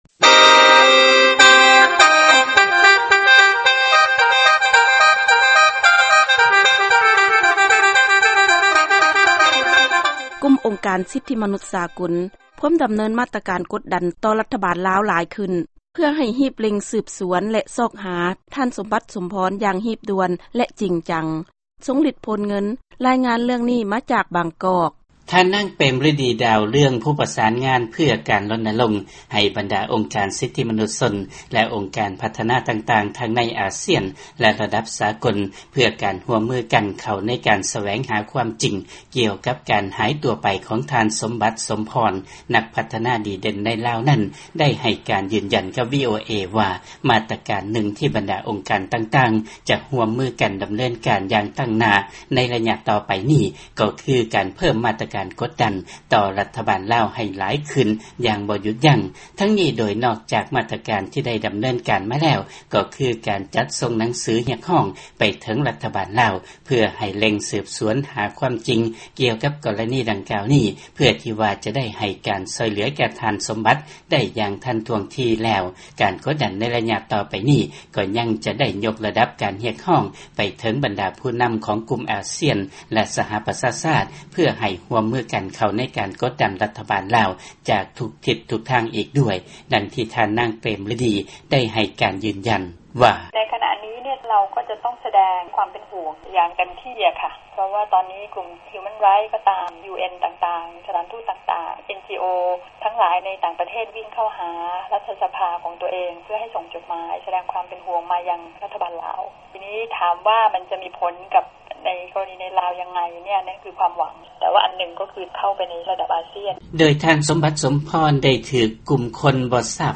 ຟັງລາຍງານການຫາຍສາບສູນຂອງທ່ານສົມບັດ ສົມພອນ